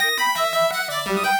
SaS_Arp03_170-E.wav